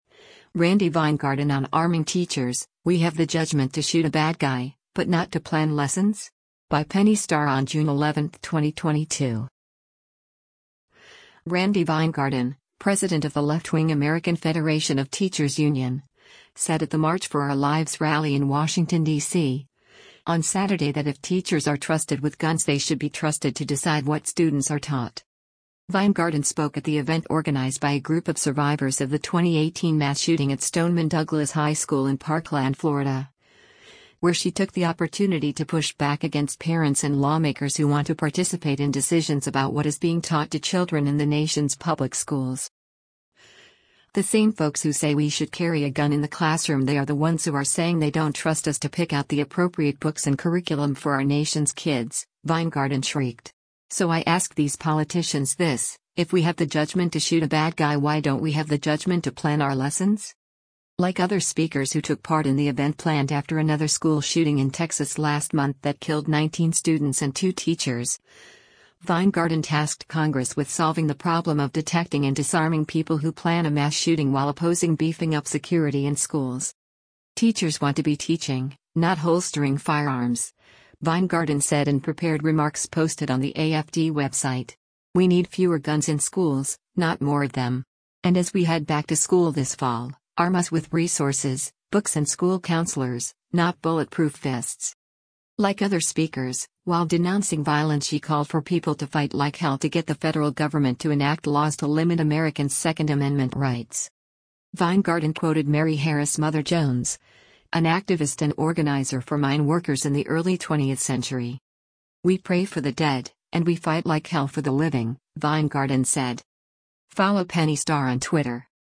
Randi Weingarten, president of the leftwing American Federation of Teachers union, said at the March for Our Lives rally in Washington, DC, on Saturday that if teachers are trusted with guns they should be trusted to decide what students are taught.